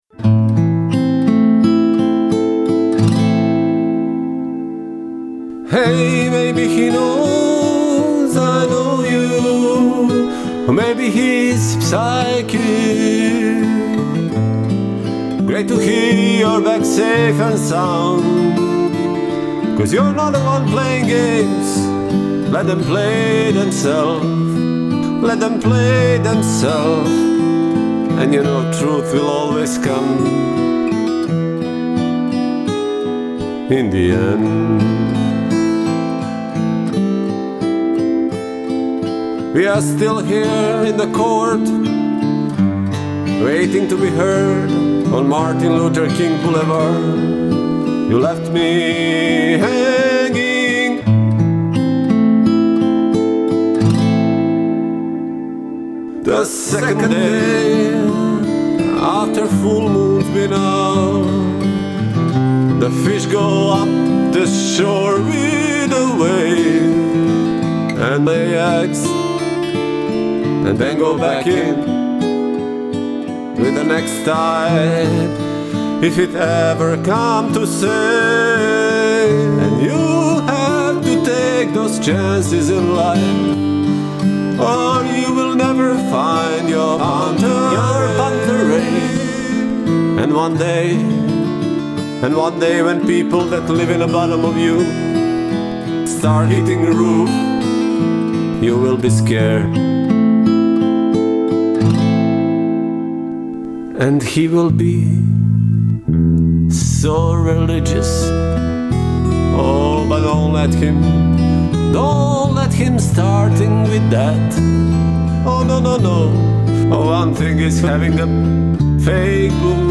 это энергичная композиция в жанре поп-рок